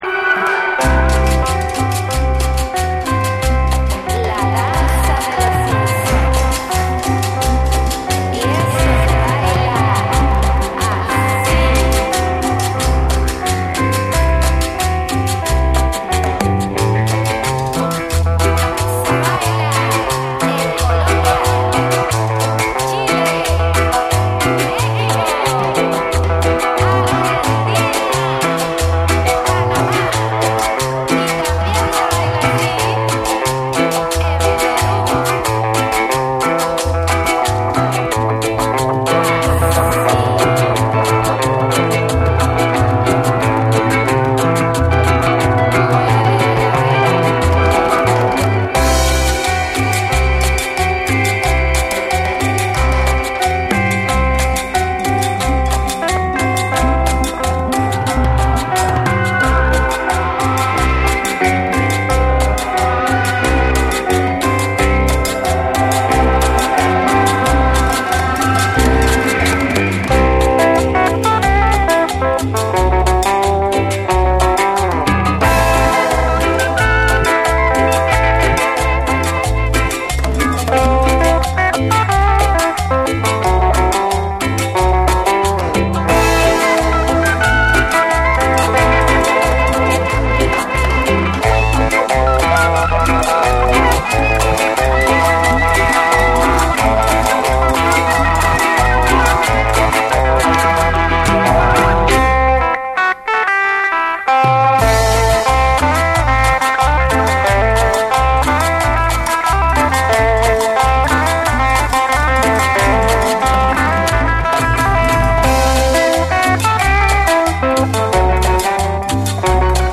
BREAKBEATS / ORGANIC GROOVE